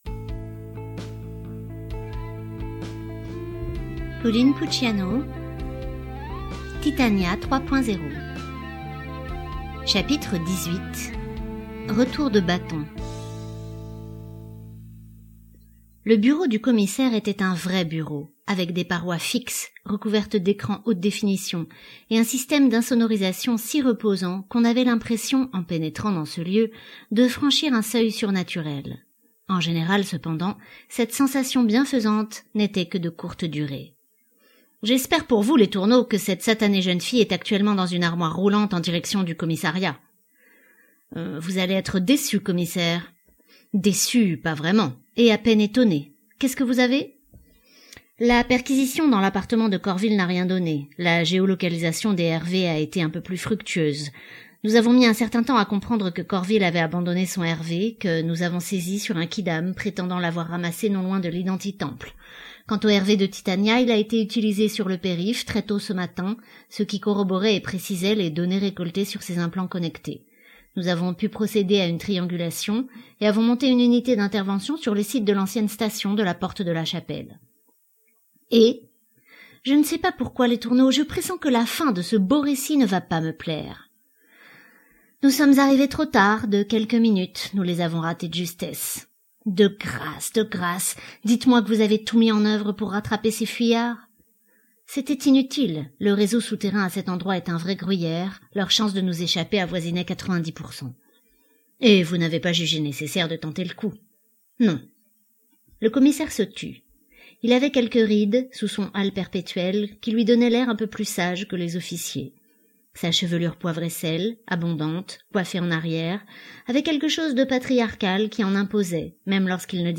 livres audio gratuits